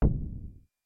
标签： MIDI-速度-20 CSHARP2 MIDI音符-37 挡泥板-色度北极星 合成器 单票据 多重采样
声道立体声